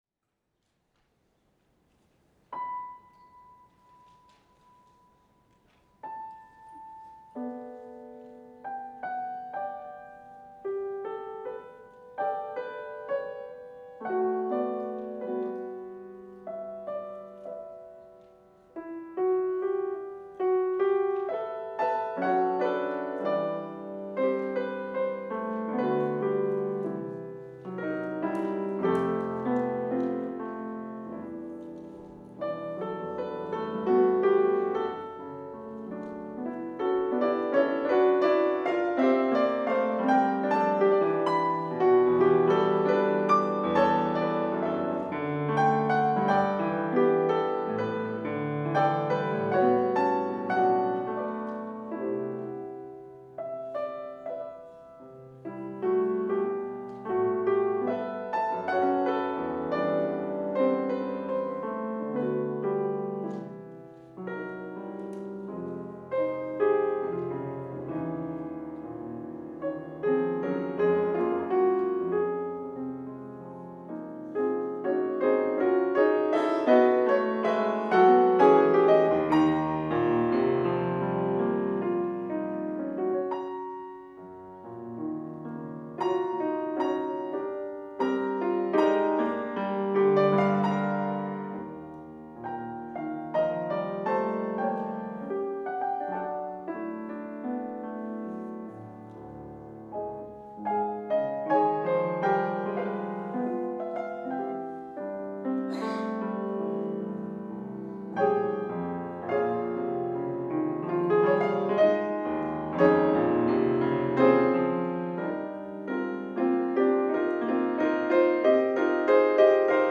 Finalizará el concierto un servidor, con el estreno absoluto de su última obra para piano: 5 Preludes, donde se podrá disfrutar de un pianismo lleno de poesía, virtuosismo y elegancia.
No te lo pierdas: lunes 4 de junio de 2018, 17:30h, en el Auditori del Conservatori Professional de Música «Josep Climent» d’Oliva.
Grabación del Preludio 1 en el día de su estreno:
piano